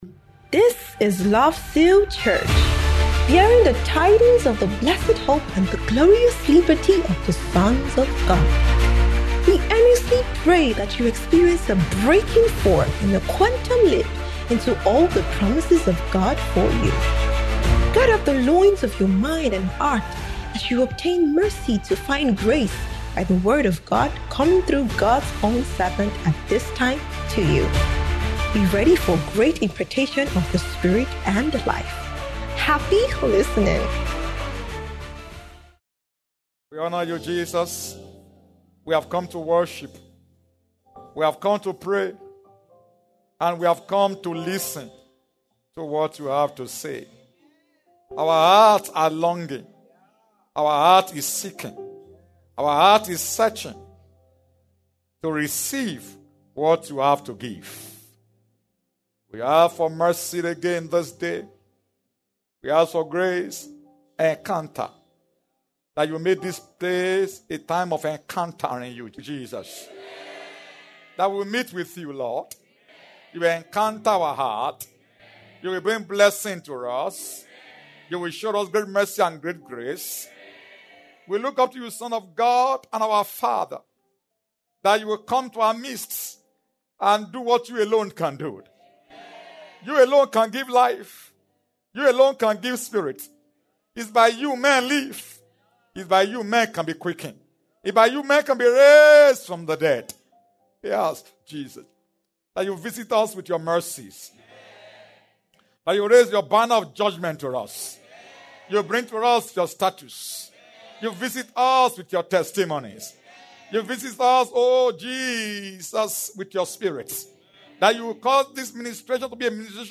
SUNDAY APOSTOLIC BREAKTHROUGH IMPARTATION SERVICE